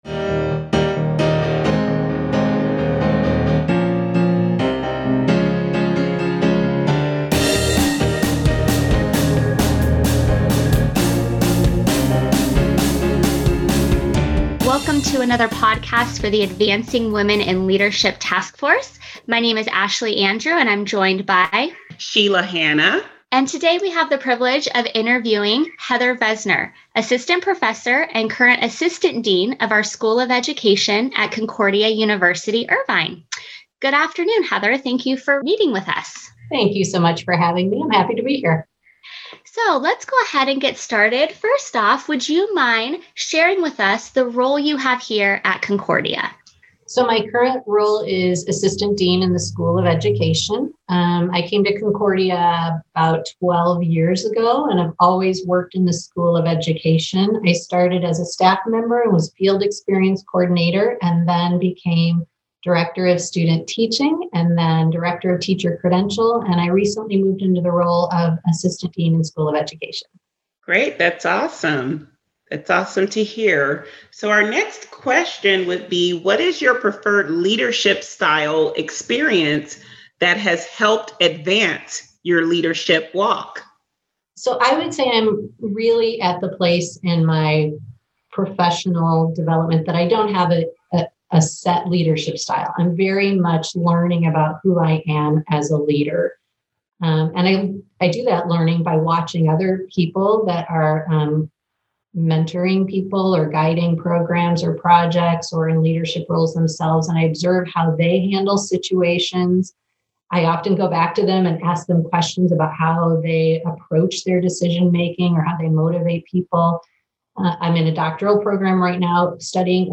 Advancing Women in Leadership AWiL Interview